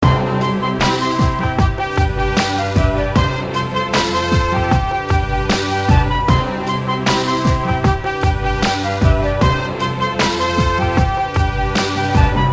breakbeat big-band classical remix
produced for live performance only (1992)